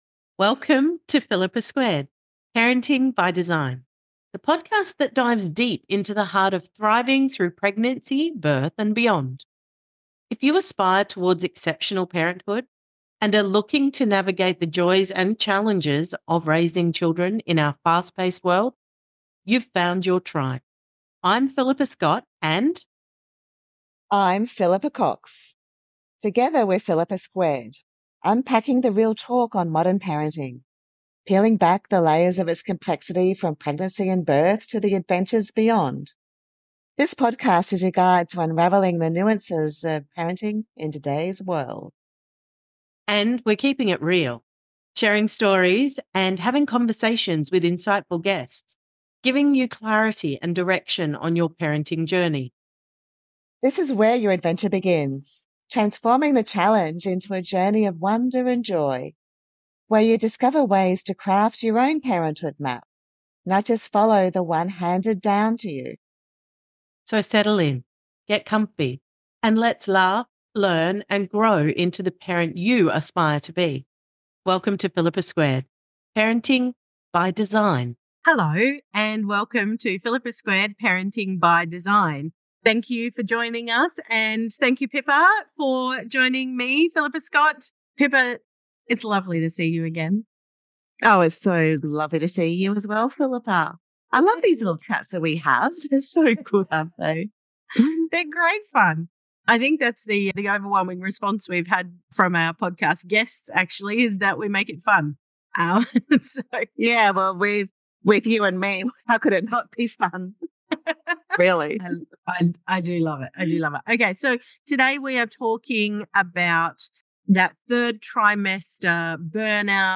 The conversation touches on the importance of rest, bonding with your newborn, and managing expectations.